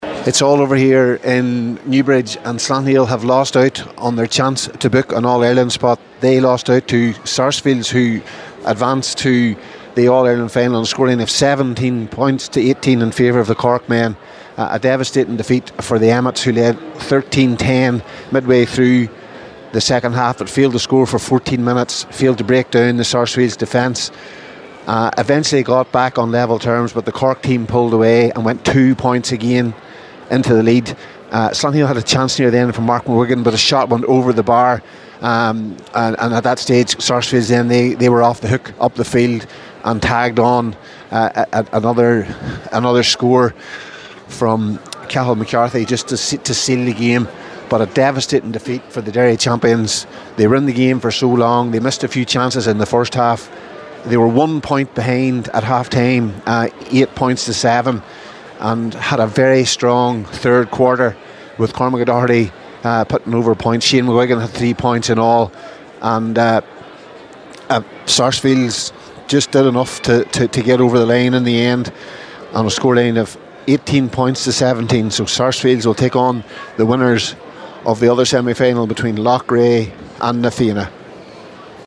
With the full time report